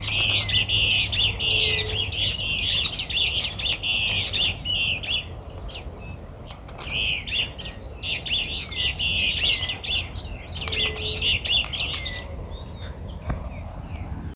Fiofío Copetón (Elaenia flavogaster)
Nombre en inglés: Yellow-bellied Elaenia
Localidad o área protegida: Reserva Privada San Sebastián de la Selva
Condición: Silvestre
Certeza: Fotografiada, Vocalización Grabada
Fiofio-copeton-1_1.mp3